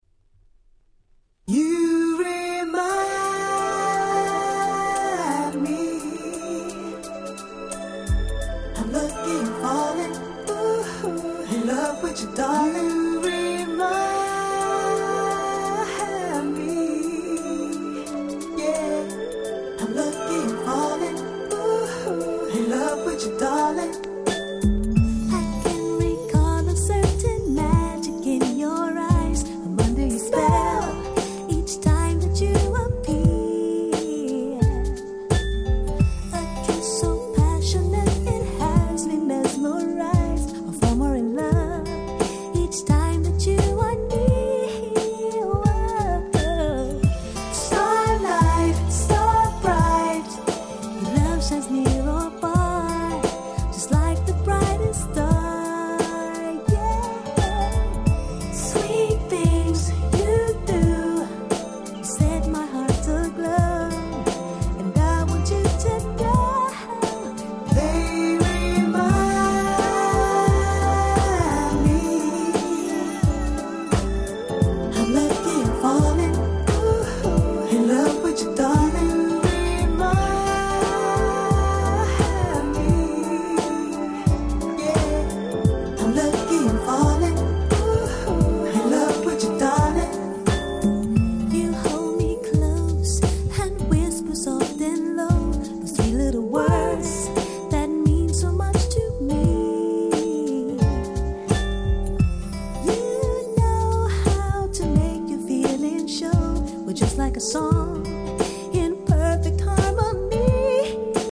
※試聴ファイルは別の盤から録音してあります。